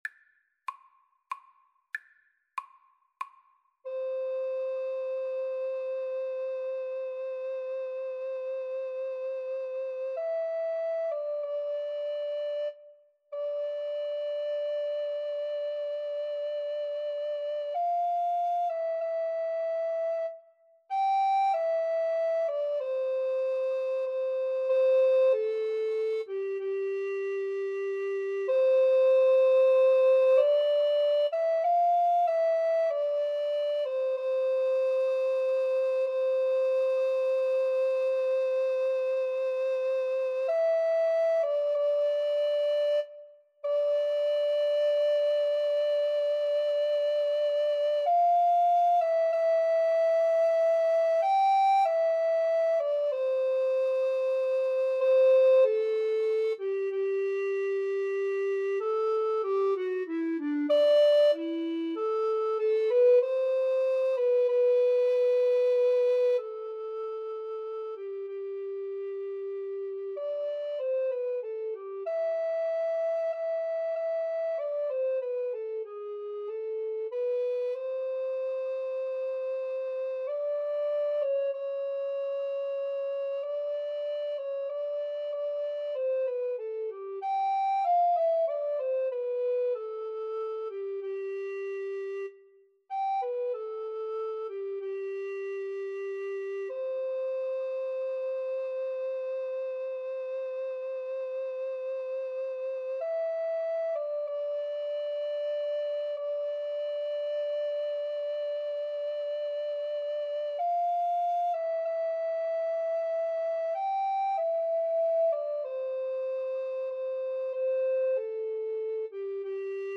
=95 Andante
3/4 (View more 3/4 Music)
Classical (View more Classical Recorder Duet Music)